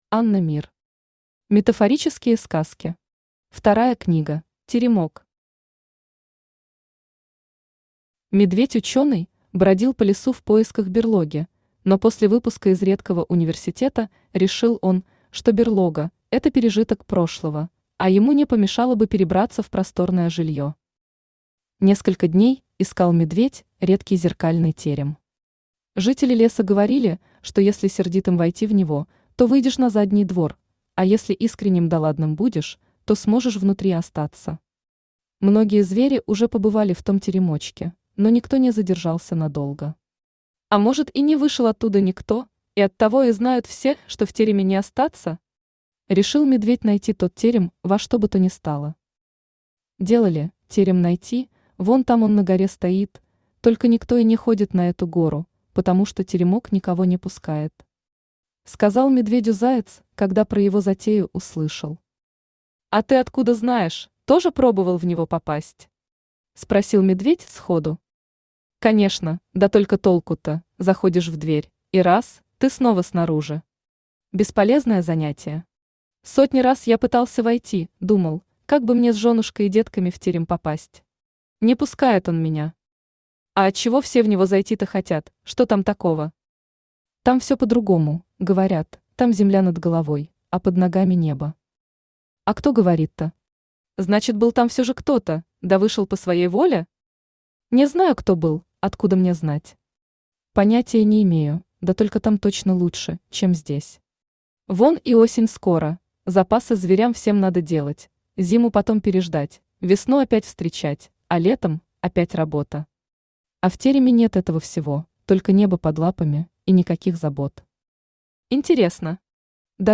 Аудиокнига Метафорические сказки. Вторая книга | Библиотека аудиокниг
Вторая книга Автор Анна Мир Читает аудиокнигу Авточтец ЛитРес.